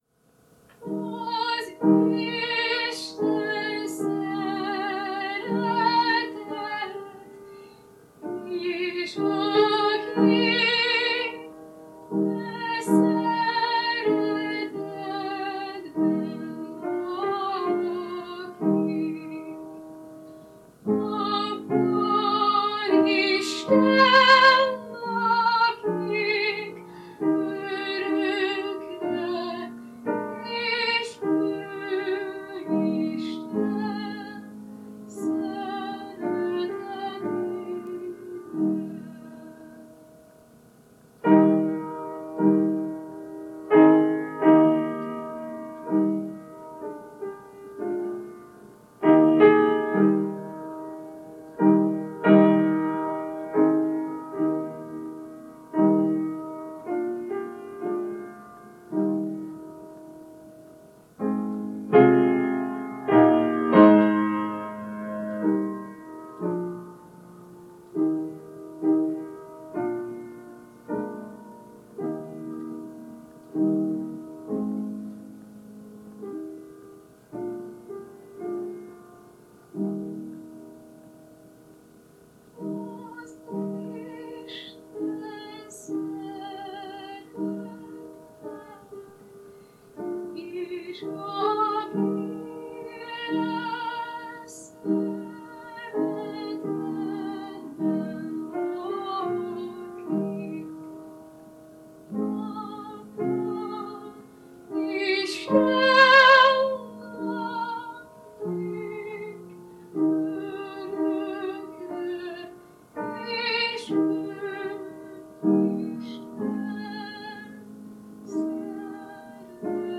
Ének
Zongora